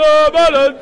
哎哟
描述：有人受伤的卡通声音。
在家中使用Audible和Yeti USB麦克风录制。
标签： 叫喊 呼喊 现场录音 尖叫 声音 嗷嗷 威廉 哎哟
声道立体声